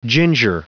Prononciation du mot ginger en anglais (fichier audio)
Prononciation du mot : ginger